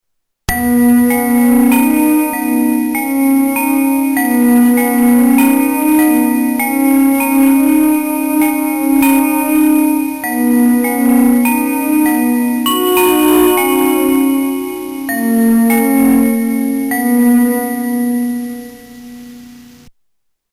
Chimes with echo
Category: Animals/Nature   Right: Personal